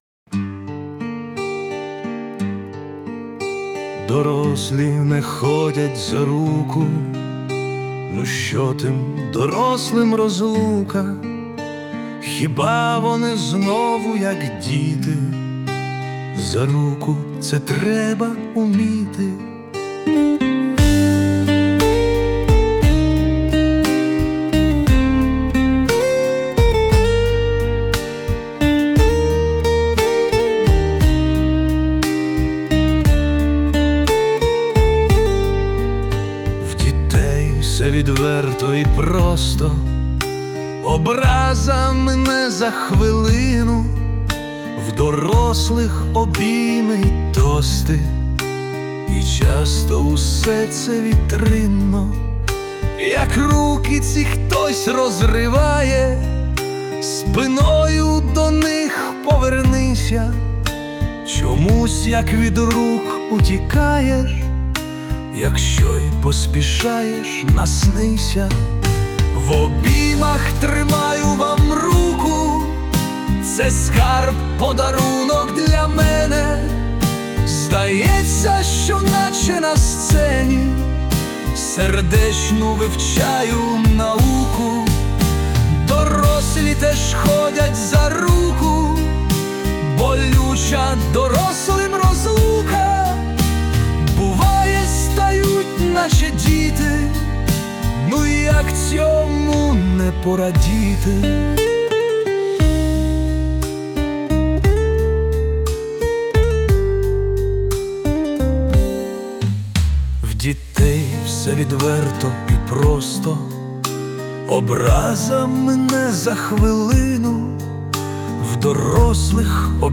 Присутня допомога SUNO
СТИЛЬОВІ ЖАНРИ: Ліричний